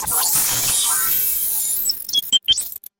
Robot Power-Up
A robot powering on with ascending electronic tones, system beeps, and motor initialization
robot-power-up.mp3